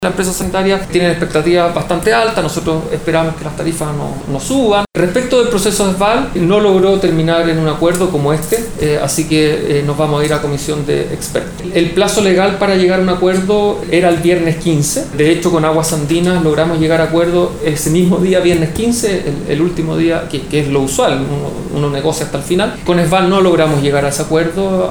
Al respecto, Jorge Rivas, superintendente de Servicio Sanitarios, indicó que las empresas sanitarias tienen expectativas bastante altas y que ellos esperan que las tarifas no suban en demasía. Explicó que a diferencia de lo ocurrido con Aguas Andinas, con Esval no lograron llegar a un acuerdo, lo que se resolverá en la comisión de expertos.